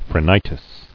[phre·ni·tis]